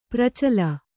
♪ pracala